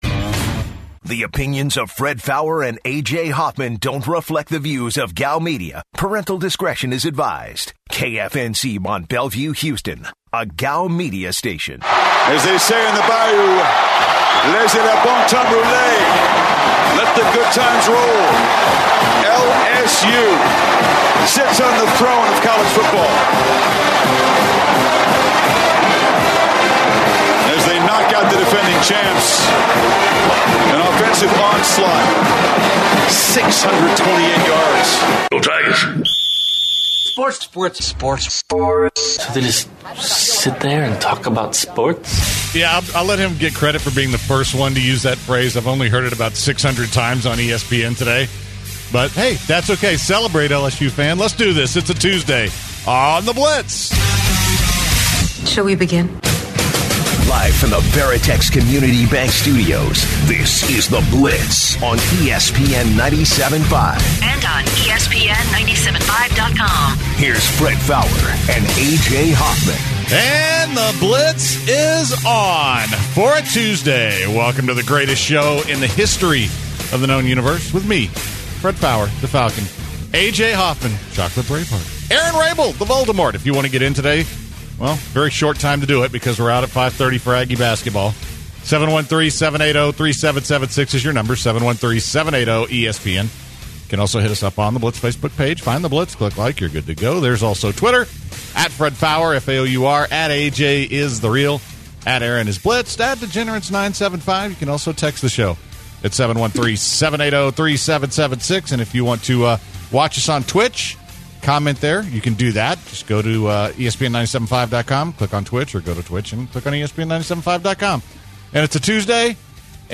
They discuss where quarterback Cam Newton will play next season, since he will be a free agent and he is coming off an injury with a sprained foot. Around the end of the show they interviewed Texas A&M’s head basketball coach Buzz Williams about their win against Vanderbilt and the way they’re playing going into conference play.